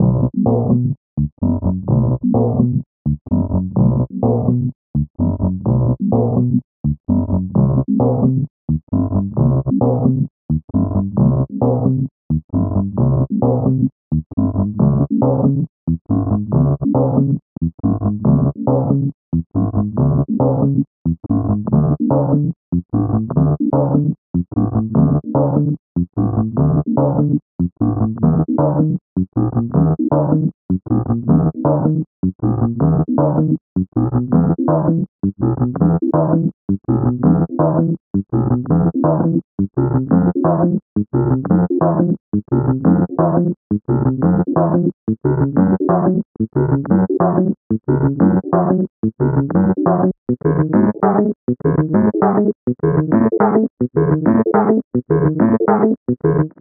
build up tempoplus [..> 2025-11-16 12:42   31M